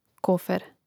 kòfer kofer